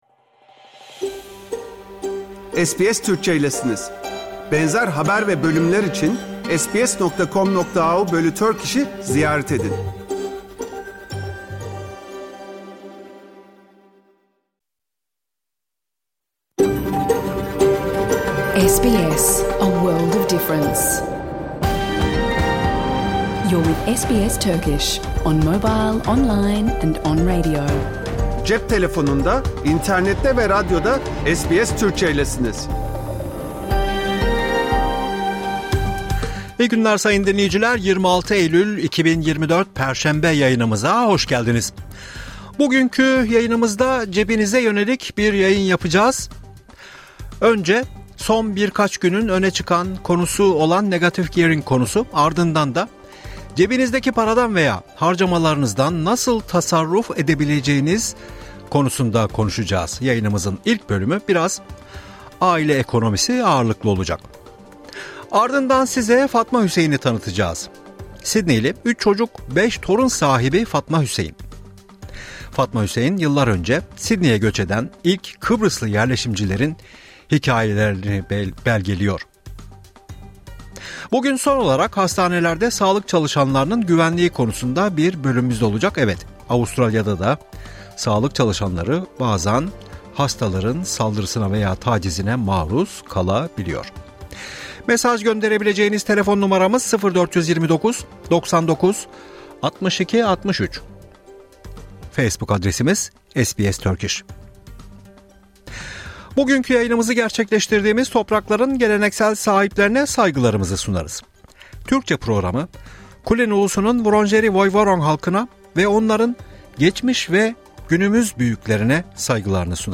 Hafta içi Salı hariç hergün her saat 14:00 ile 15:00 arasında yayınlanan SBS Türkçe radyo programını artık reklamsız, müziksiz ve kesintisiz bir şekilde dinleyebilirsiniz.